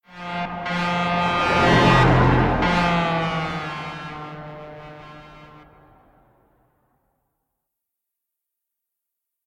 Truck Horn Passing By Right To Left
Big Big-Truck Drive Driving-By Highway Hit-Horn Hitting Horn sound effect free sound royalty free Voices